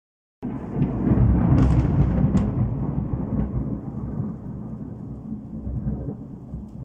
Thunder ⚡⛈ Sound Effects Free Download